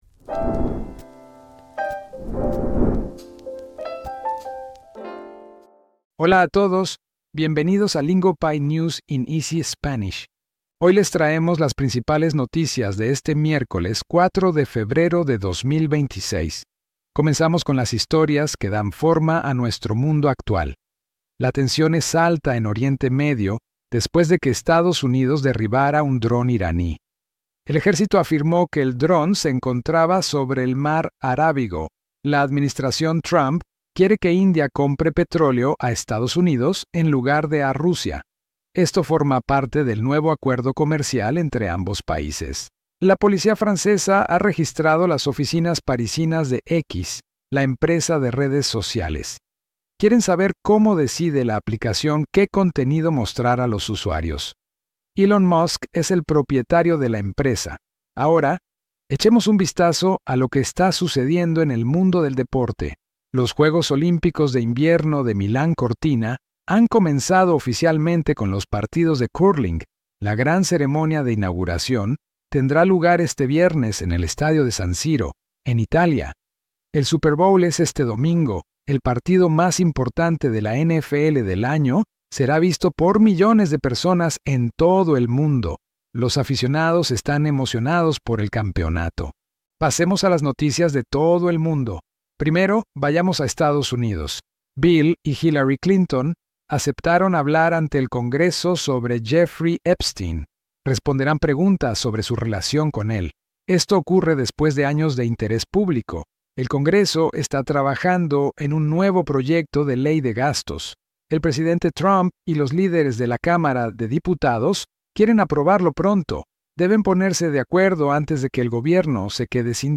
Lingopie’s News in Easy Spanish keeps you informed through real global headlines delivered in clear, beginner-friendly Spanish so you can follow along without the stress.